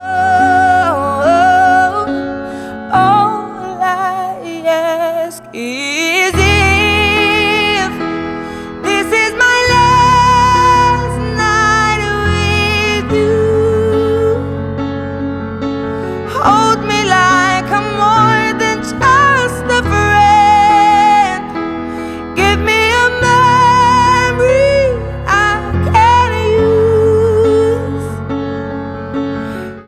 • Pop